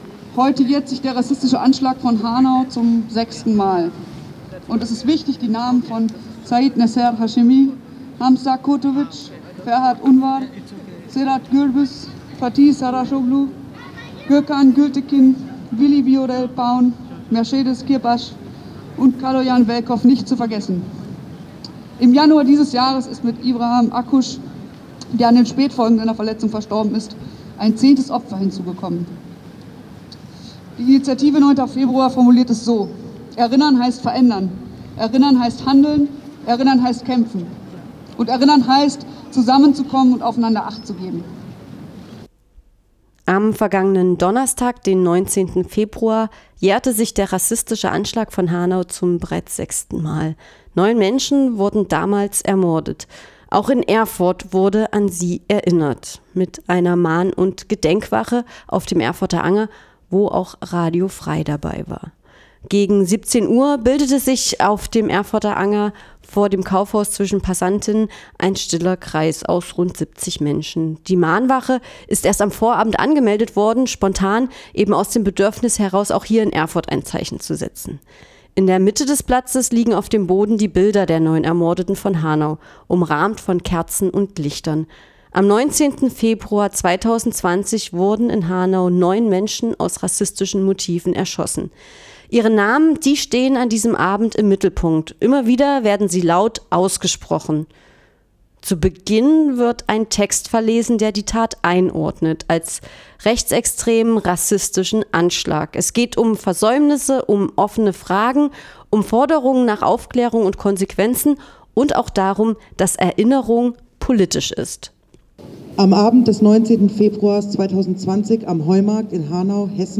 Auch in Erfurt wurde an sie erinnert � mit einer Mahn- und Gedenkwache auf dem Anger.
Radio F.R.E.I. war vor Ort.